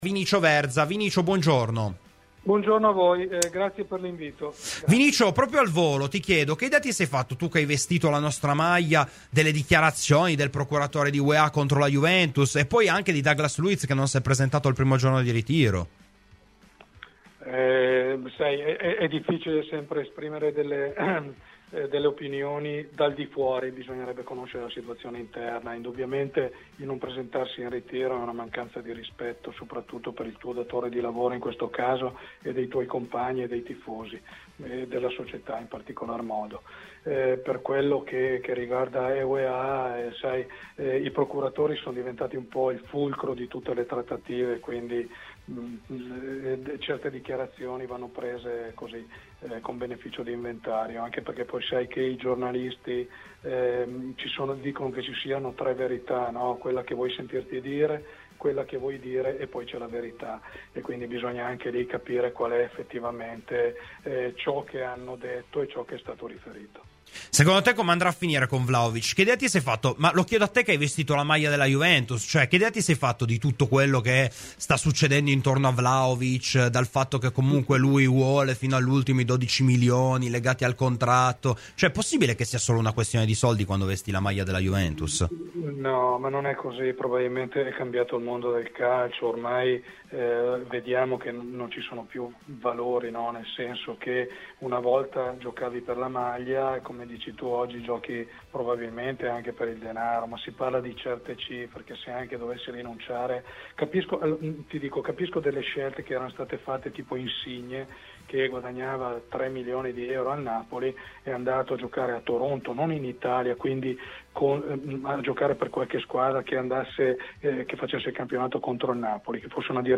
L'ex centrocampista Vinicio Verza, cresciuto nella Juventus e in bianconero dal 1977 al 1981, è intervenuto oggi ai microfoni di Radio Bianconera nel corso della “Rassegna Stramba” per commentare i casi che stanno movimentando il mercato di Comolli: “È difficile esprimere delle opinioni dal di fuori, ma indubbiamente non presentarsi al ritiro è una mancanza di rispetto nei confronti di tutto l'ambiente bianconero - le sue parole per commentare la decisione di Douglas Luiz di disertare l'appuntamento del 24 luglio alla Continassa - Per quanto riguarda Weah, al giorno d'oggi sappiamo il potere che hanno assunto i procuratori, per cui certe dichiarazioni le prenderei sempre con il beneficio dell'inventario.